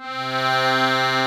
C3 ACCORDI-R.wav